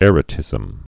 (ĕrə-tĭzəm)